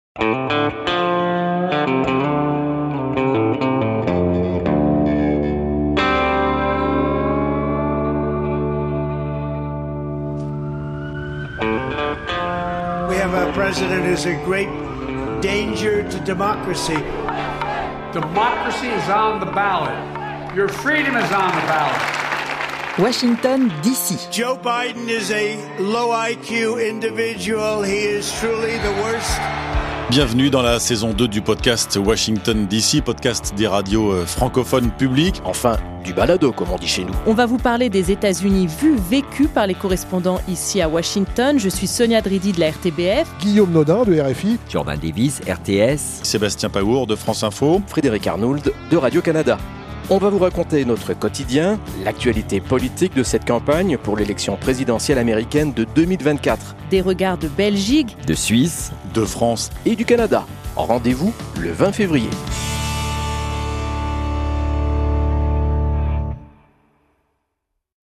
Bande-annonce – Washington d'ici
Bande-annonce_Washington-d-ici_saison-2.mp3